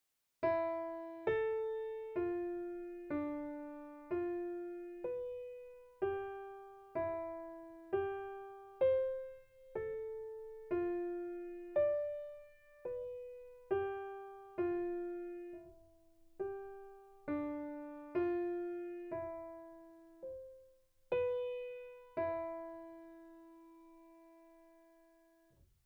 Exercise 3 – Piano
Pozzoli_1_Example3_piano.mp3